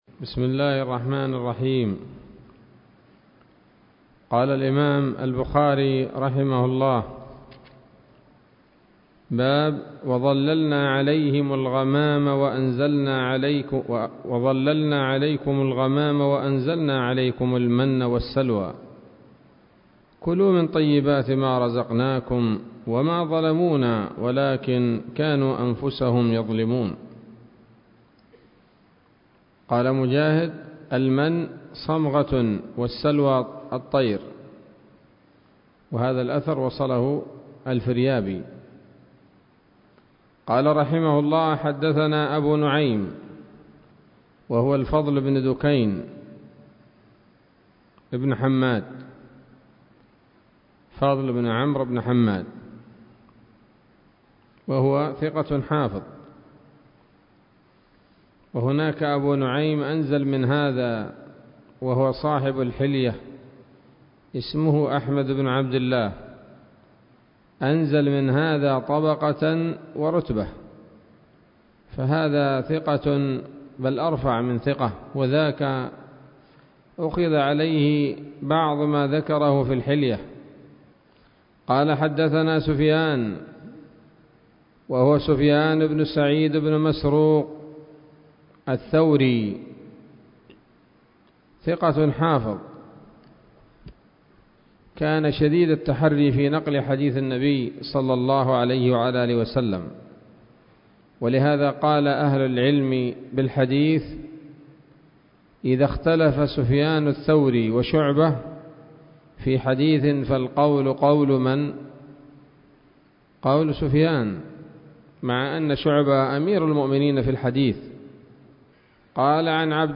الدرس الخامس من كتاب التفسير من صحيح الإمام البخاري